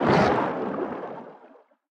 Sfx_creature_squidshark_swimangry_os_04.ogg